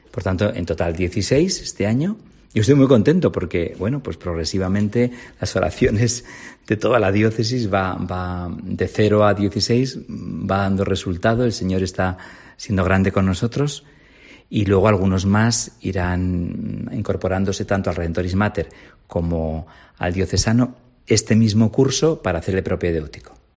Don Juan Carlos Elizalde, obispo de Vitoria